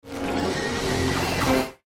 File:Bacillus roar.mp3
Bacillus_roar.mp3